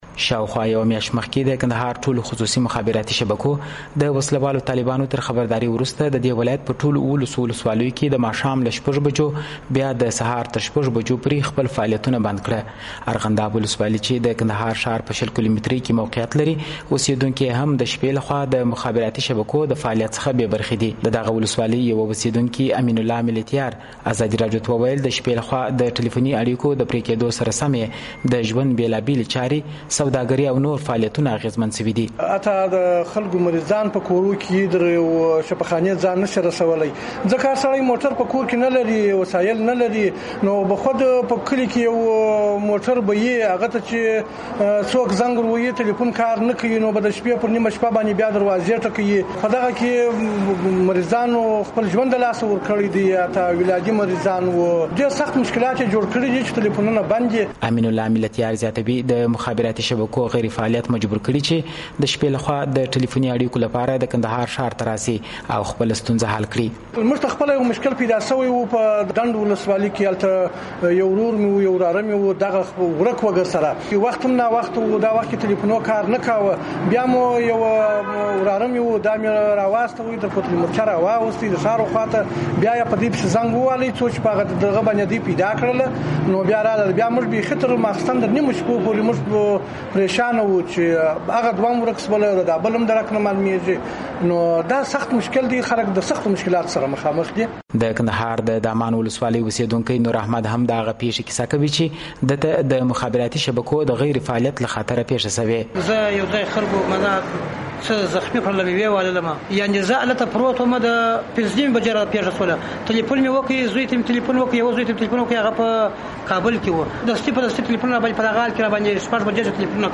کندهار کې د مخابراتي شبکو د ستونزو په اړه راپور